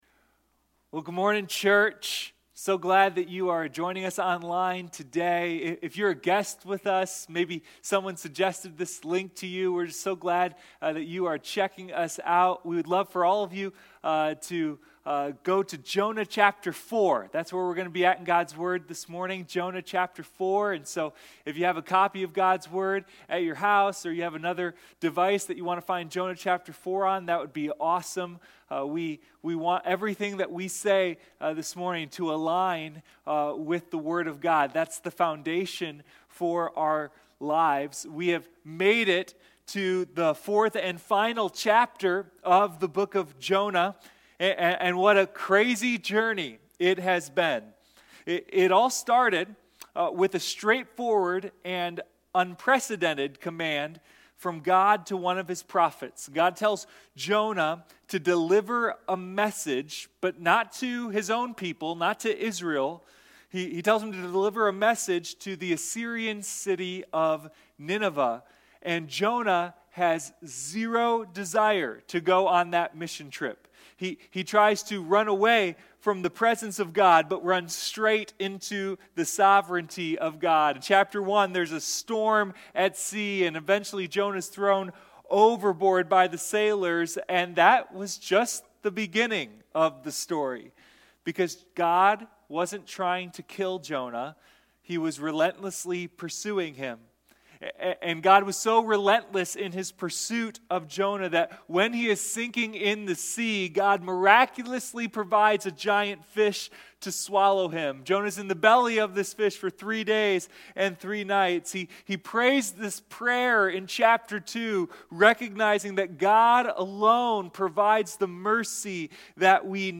Sunday Morning Jonah: a deep dive into God's mercy